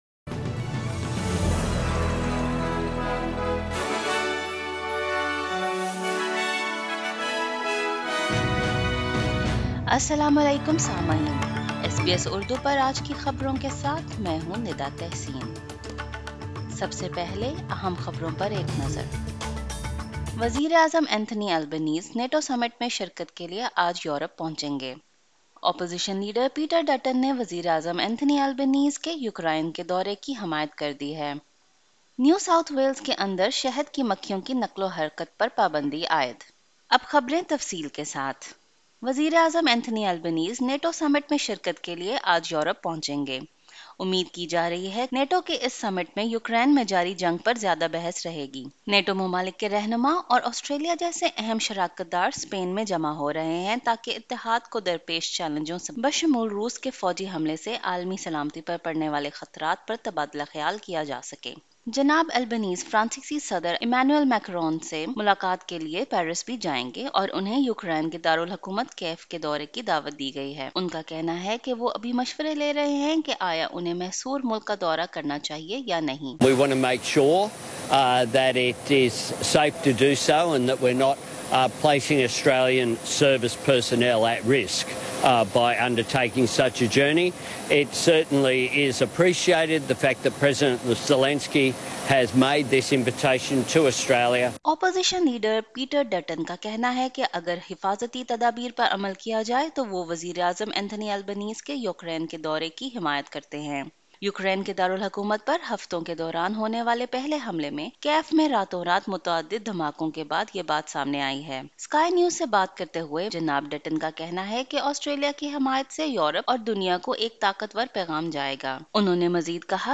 The Prime Minister leaves Australia for a crucial NATO security bloc summit in Spain. G-7 nations to spend billions on infrastructure projects during the next five years. Opposition supports Prime Minister visiting Ukraine if safety precautions are followed… Listen to detailed news in Urdu language.